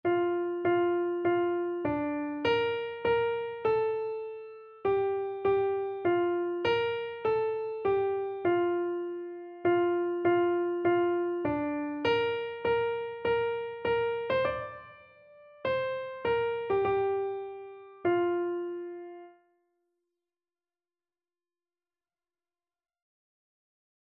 Keyboard version
Free Sheet music for Keyboard (Melody and Chords)
4/4 (View more 4/4 Music)
Keyboard  (View more Easy Keyboard Music)
Classical (View more Classical Keyboard Music)